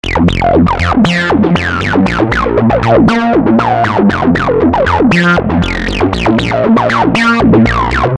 Free-acid-bass-loop-118-bpm-download.mp3